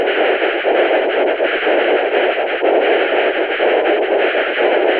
Het apparaat bestaat erin een ruissignaal uit te zenden in de 500 Hz en de 2000 Hz banden.  Deze signalen zijn bovendien gemoduleerd met frequenties die overeen komen met de spraak.
Rasti-geluid (5 sec, mono).wav